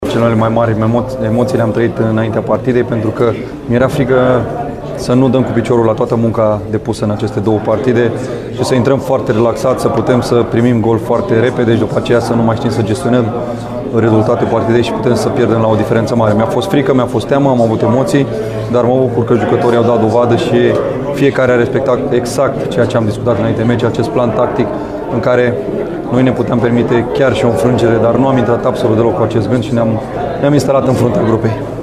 Antrenorul Mirel Rădoi a vorbit după meci despre emoţiile trăite mai intens cu precădere înainte de joc: